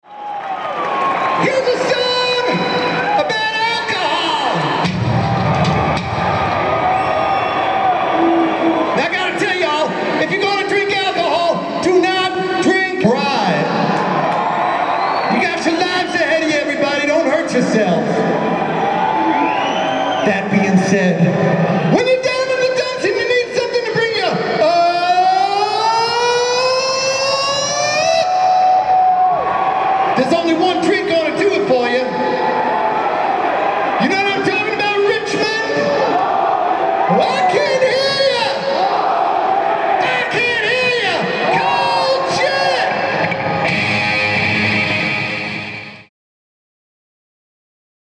It's not a musical album, thankfully, but 70 tracks (about 60 minutes) of the most embarrassing rock show crowd work courtesy of Kiss' aging Star Child.